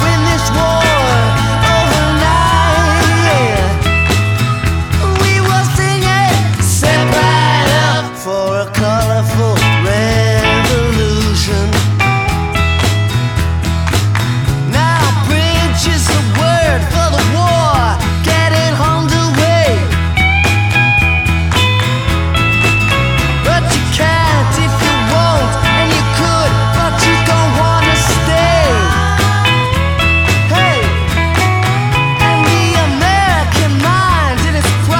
Жанр: Рок / Электроника